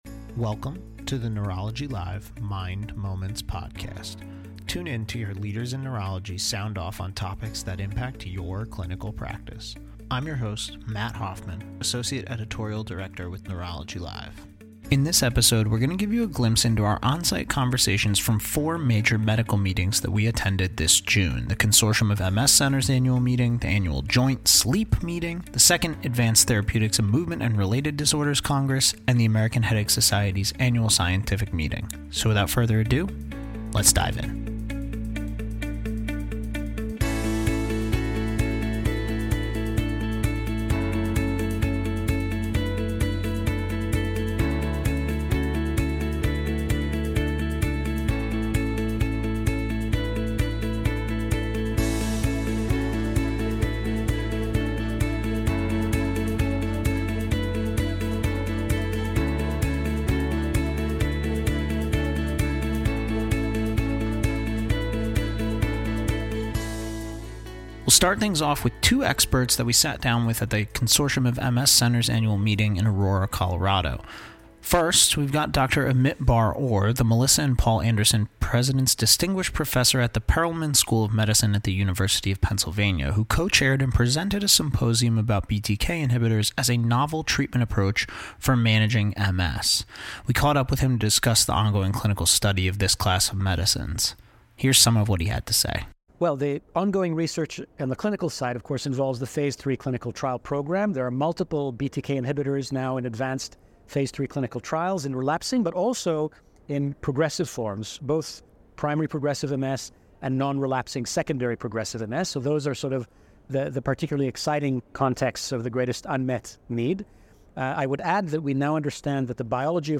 In this episode, we spoke with a variety of specialists in neurology who presented research and gave talks at 4 recent major medical meetings—the Consortium of MS Centers Annual Meeting, the Annual Joint SLEEP Meeting, the Advanced Therapeutics in Movement and Related Disorders Congress, and the American Headache Society’s Annual Scientific Meeting.